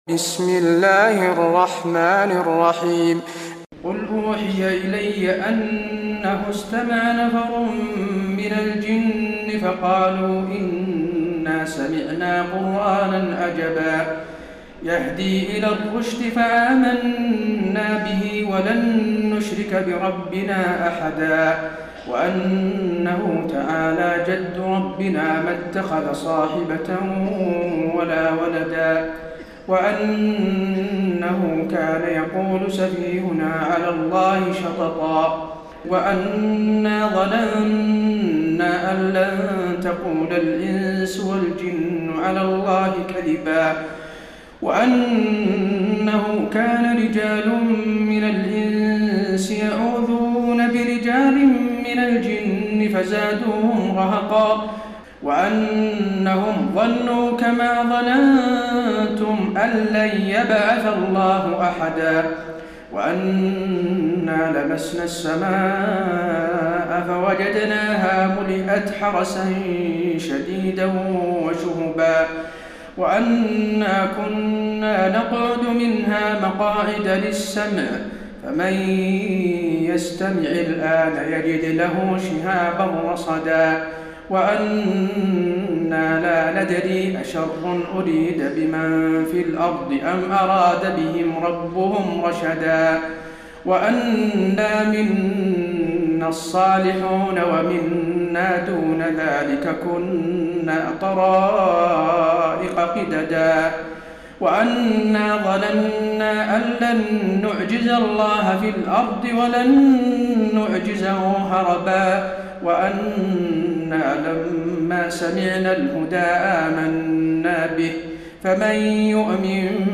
تراويح ليلة 28 رمضان 1432هـ من سورة الجن الى المرسلات Taraweeh 28 st night Ramadan 1432H from Surah Al-Jinn to Al-Mursalaat > تراويح الحرم النبوي عام 1432 🕌 > التراويح - تلاوات الحرمين